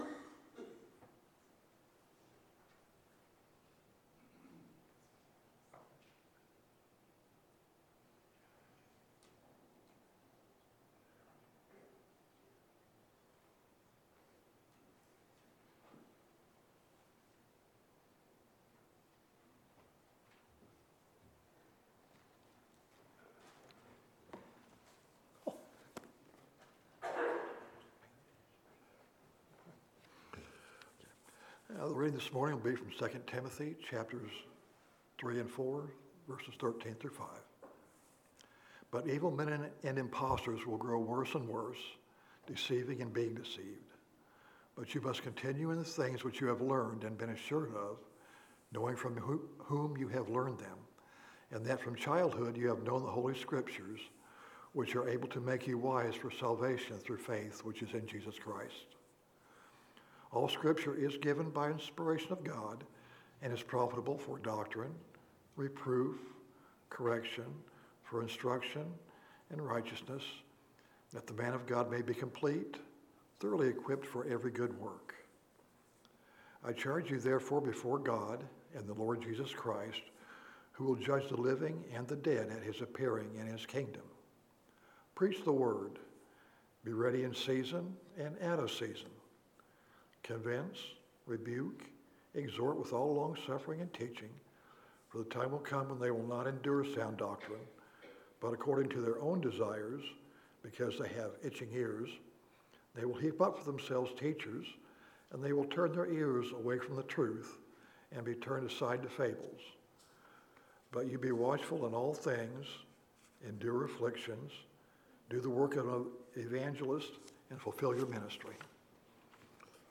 Scripture Reading – 2 Timothy 3:13 – 4:5